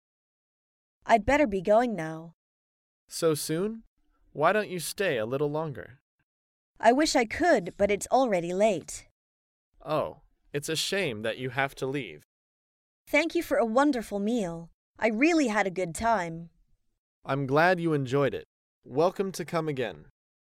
在线英语听力室高频英语口语对话 第341期:会餐道别的听力文件下载,《高频英语口语对话》栏目包含了日常生活中经常使用的英语情景对话，是学习英语口语，能够帮助英语爱好者在听英语对话的过程中，积累英语口语习语知识，提高英语听说水平，并通过栏目中的中英文字幕和音频MP3文件，提高英语语感。